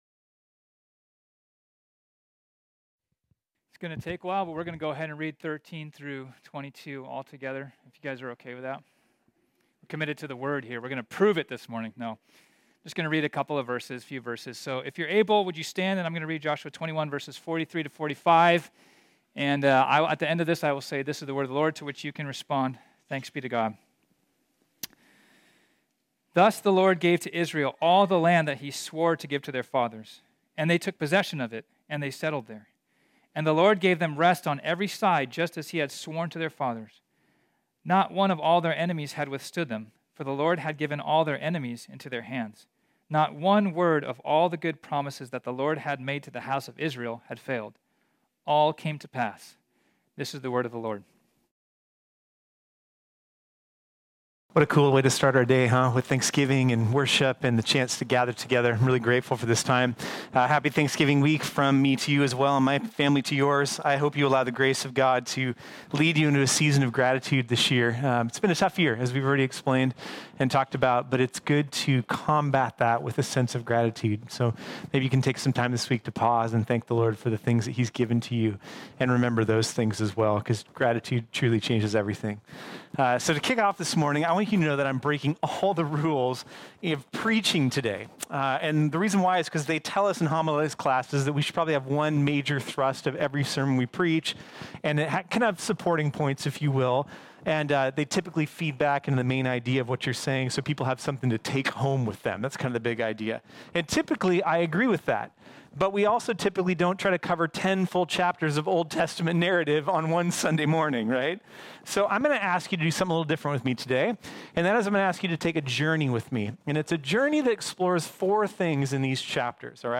This sermon was originally preached on Sunday, November 22, 2020.